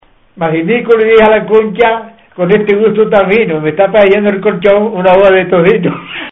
Materia / geográfico / evento: Canciones eróticas Icono con lupa
Zafarraya (Granada) Icono con lupa
Secciones - Biblioteca de Voces - Cultura oral